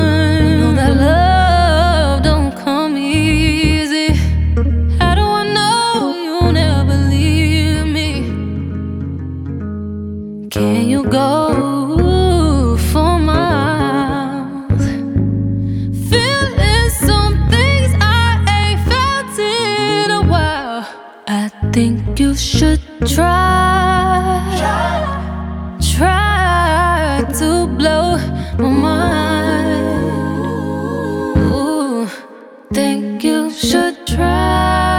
Жанр: Соул / R&b / Русские